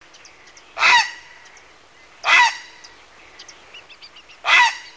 Jeune héron au nid
heron.mp3